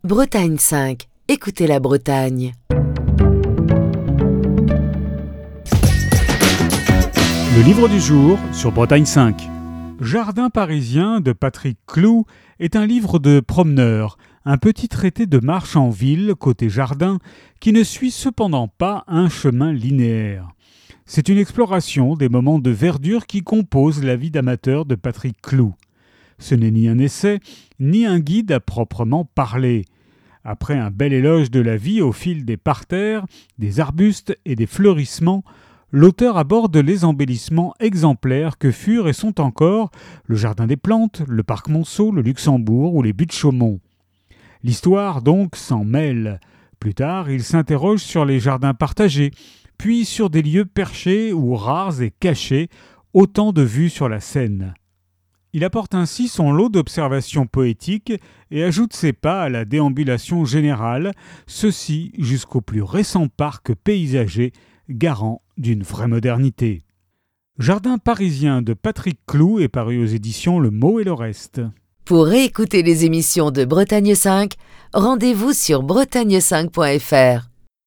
Chronique du 18 juin 2025.